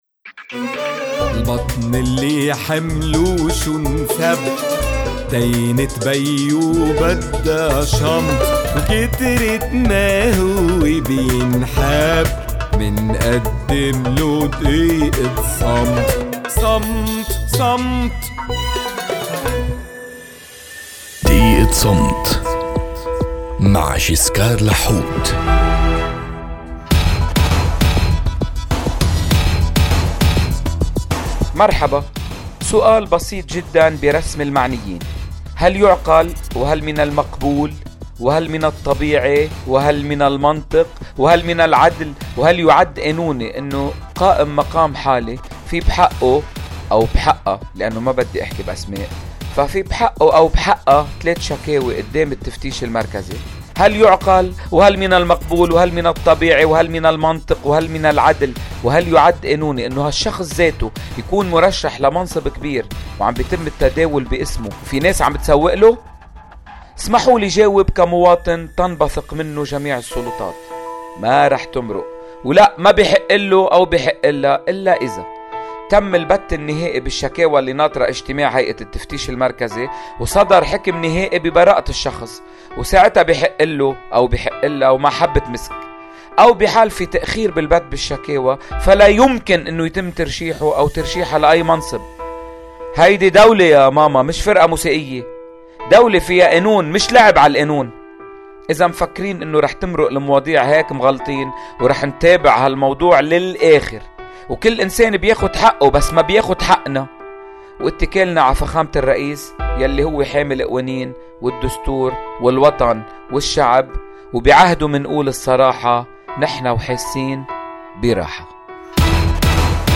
إذاعة “صوت المدى” (92.5 FM)